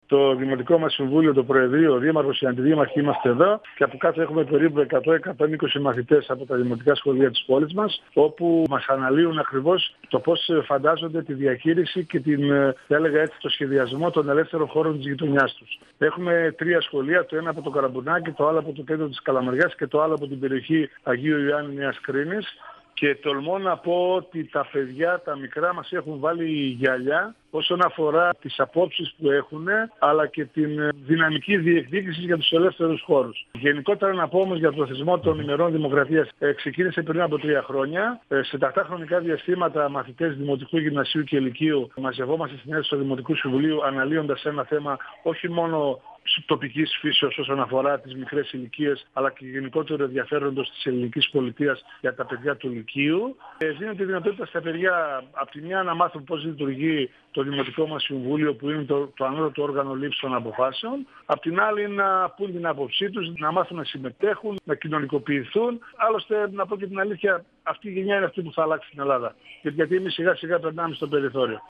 Ο δήμαρχος Καλαμαριάς Θεοδόσης Μπακογλίδης, στον 102FM του Ρ.Σ.Μ. της ΕΡΤ3
Συνέντευξη